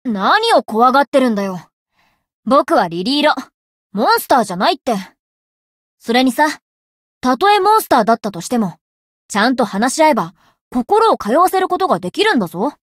灵魂潮汐-莉莉艾洛-人偶初识语音.ogg